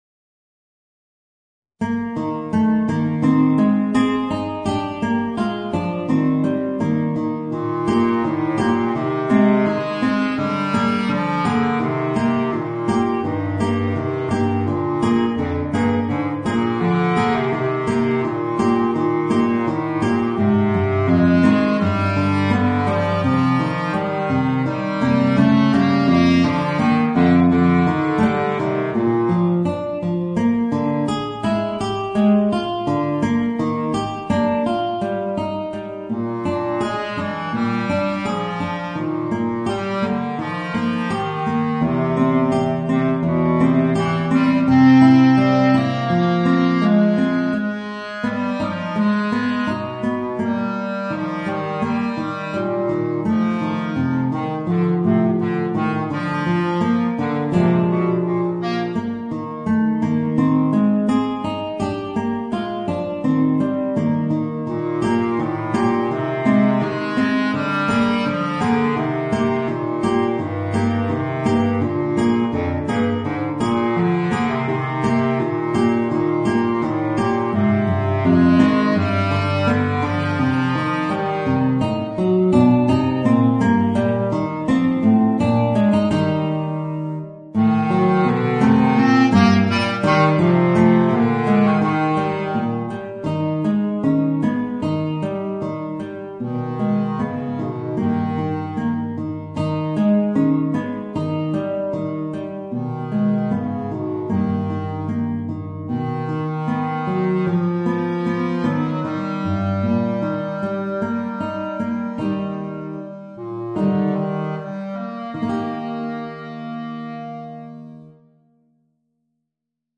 Voicing: Bass Clarinet and Guitar